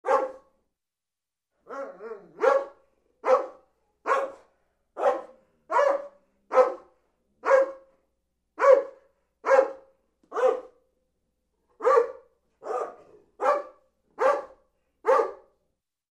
Звуки собак
Доберман лай